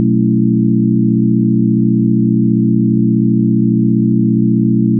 Here is a simple 6 channel 24 bit/ 96 kHz multi-tone multichannel wav file.
The file was synthesized programatically using a C# application (to be posted). The wav amplitude is 83% of peak digital and the duration is exactly 5 seconds.
The data for each of the 6 channels corresponds to the note sequence of the B11 musical chord.
The note sent to the Sub channel is an octave lower than the next highest note which targets the Front Left channel.